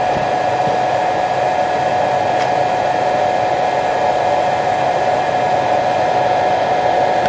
电脑
描述：来自电脑的噪音。记录在Zoom H6上。
Tag: 工业 机械 机器 计算机 机器 工厂 冷却器 噪声